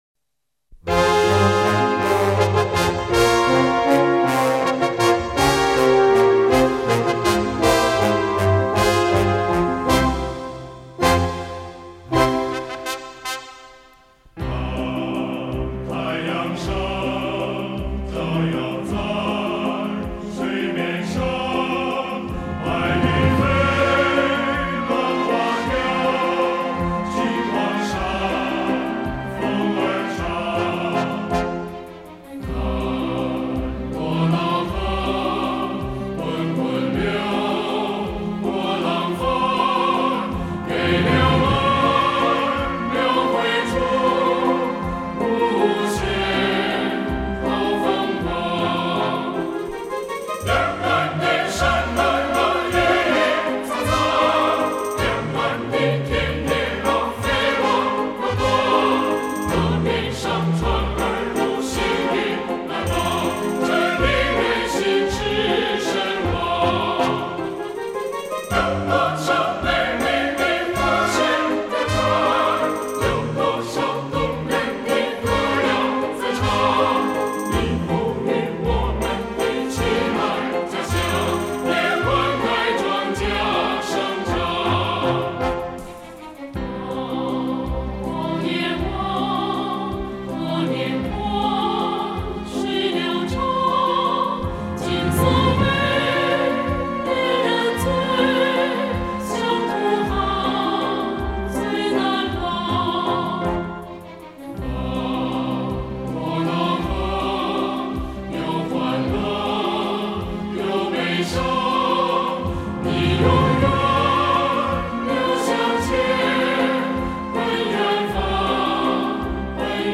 当年流行于中国大陆的国外经典民歌 经典的演绎版本 最想听的怀旧声音 最隽永难忘的音符